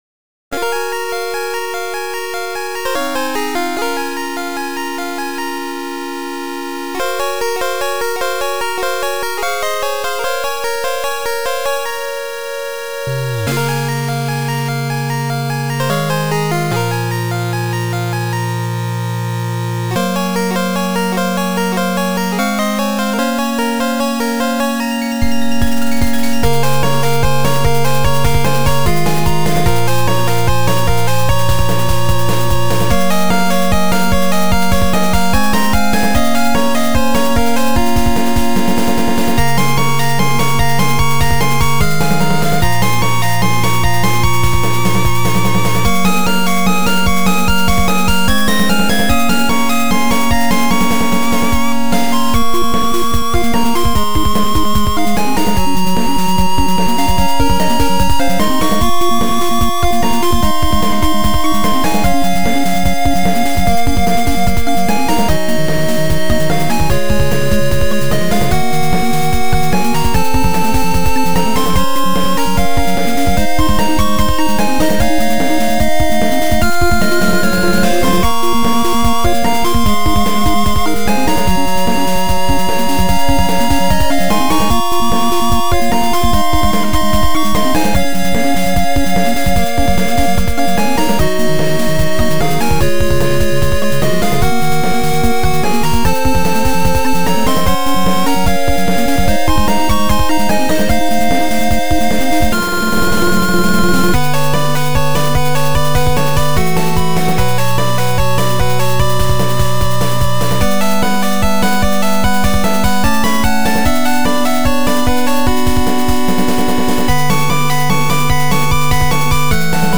ファミコン音源アレンジ
このページの楽曲は全て、フリーのFC音源サウンドドライバppmckを使って制作しています。